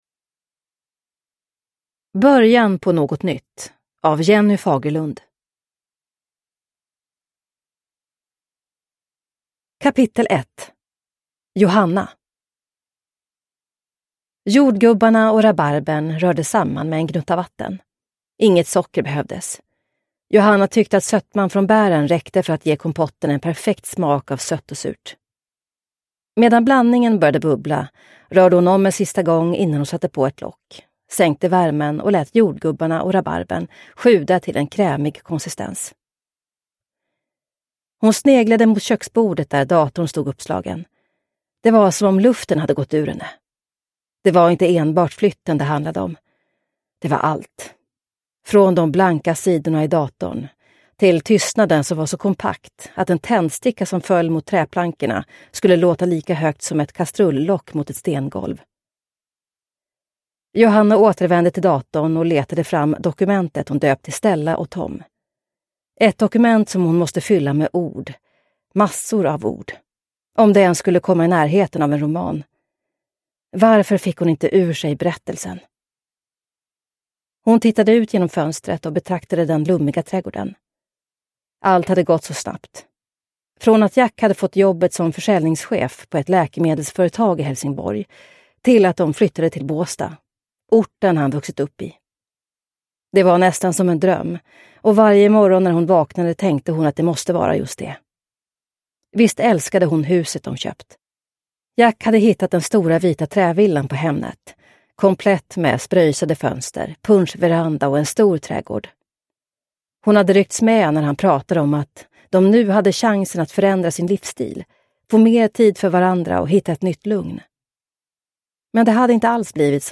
Början på något nytt – Ljudbok – Laddas ner